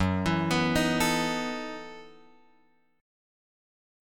F# Minor 13th